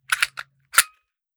9mm Micro Pistol - Cocking Slide 002.wav